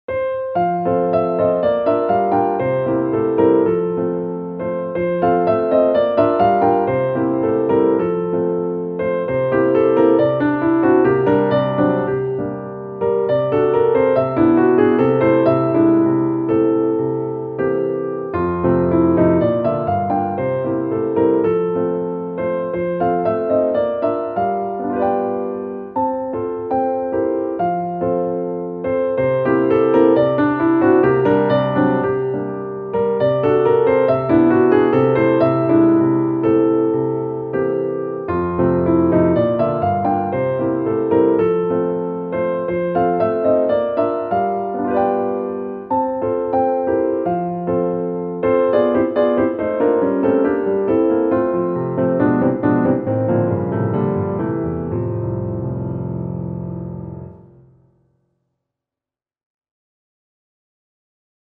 ♪サウンドプログラマ制作の高品質クラシックピアノ。